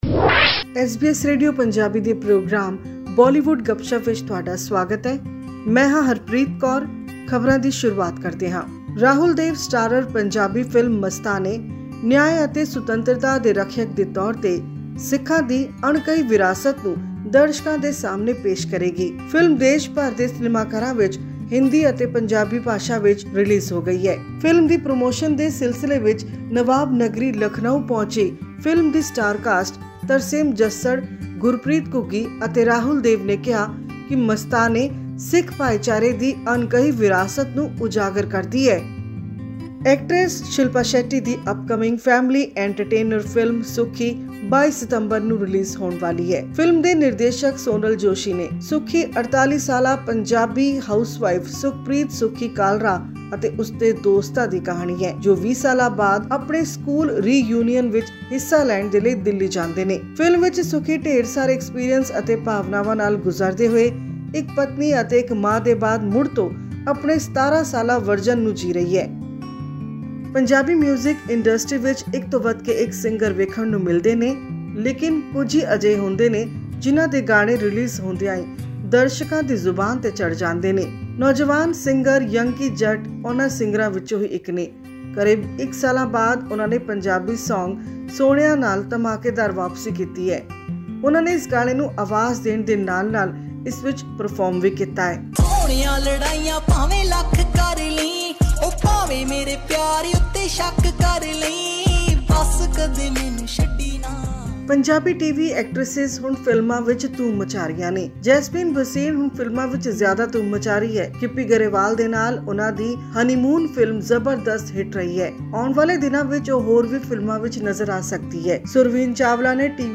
Listen to our weekly news bulletin from Bollywood to know more about upcoming movies and songs.